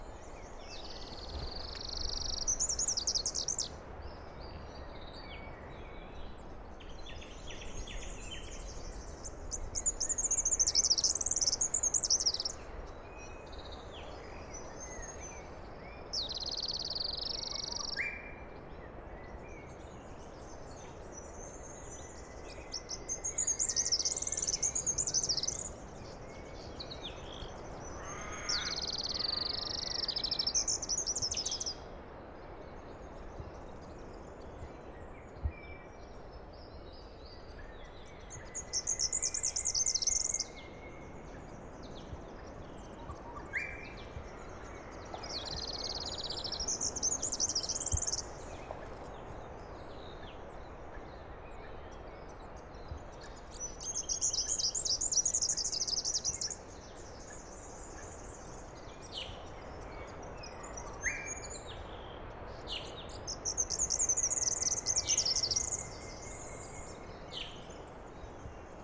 Superb Fairywren
Malurus cyaneus
Songs & Calls
The Superb Fairy-wren gives a series of high pitched trills, which are given by both sexes. The male often extends these trills into song.
Superb-Fairywren-Malurus-cyaneus.mp3